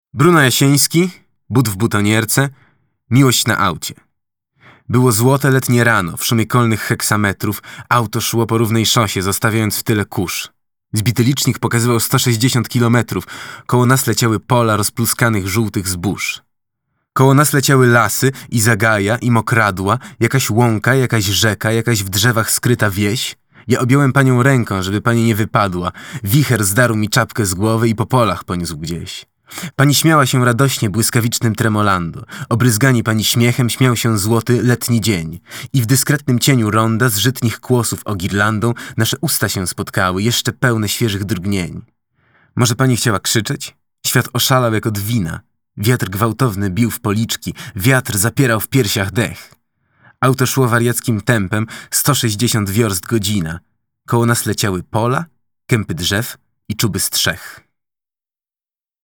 audiobook | Bruno Jasieński | But w butonierce | Miłość na aucie | wiersz